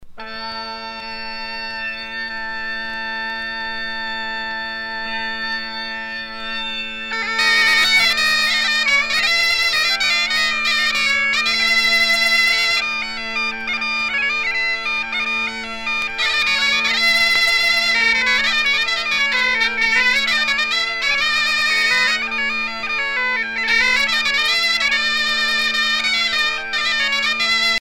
Usage d'après l'analyste gestuel : danse ;
Pièce musicale éditée